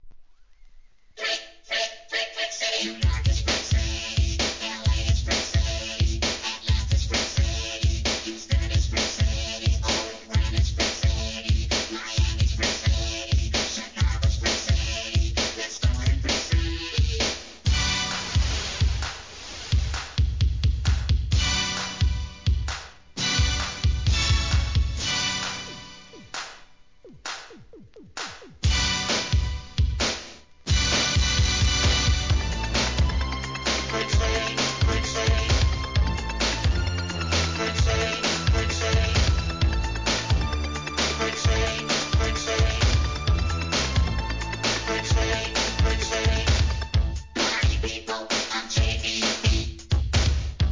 HIP HOP/R&B
西海岸のエレクトロOLD SCHOOL HIP HOPコンピレーション